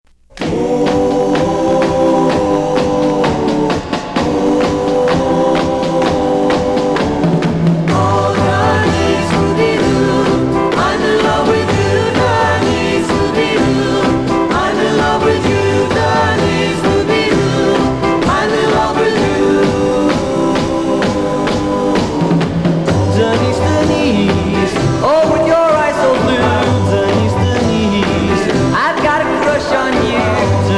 a classic Doo-Wop hit from the early 60's